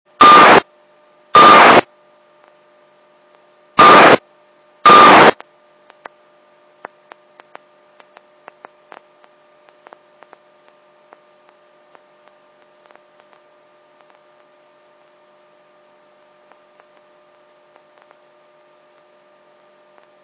pomeha1.wav